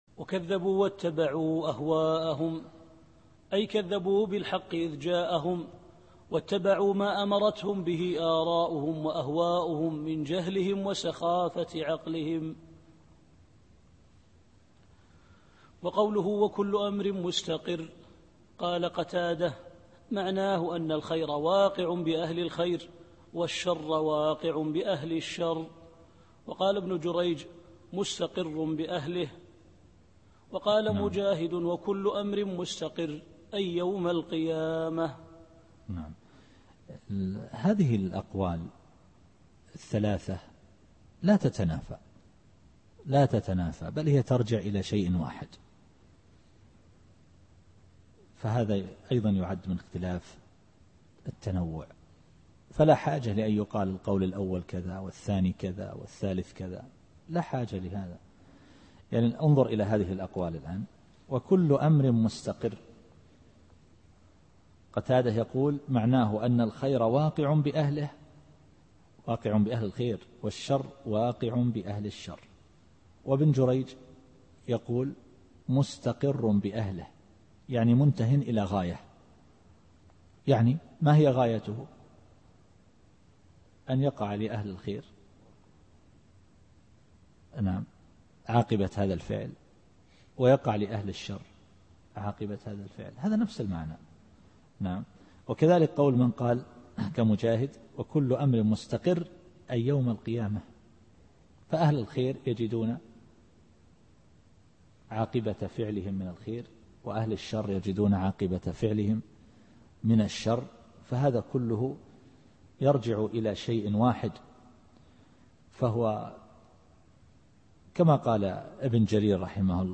التفسير الصوتي [القمر / 3]